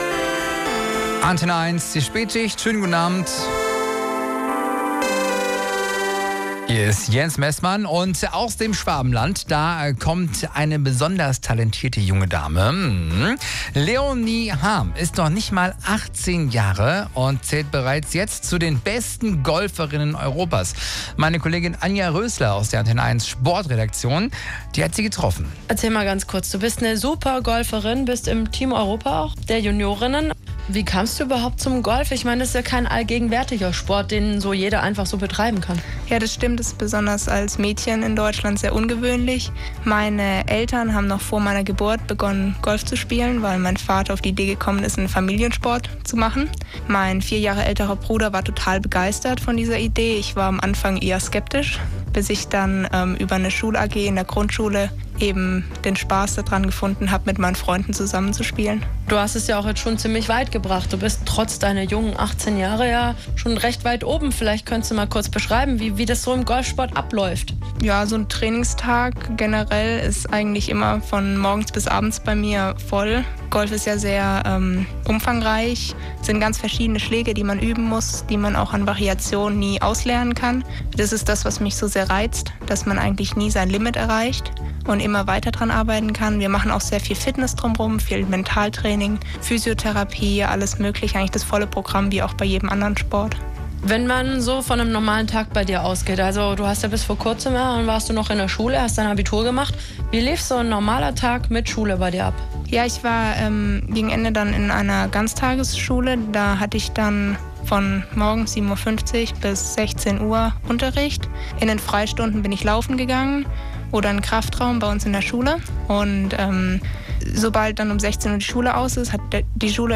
Radiointerview
Interview